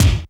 HR16B   BD 4.wav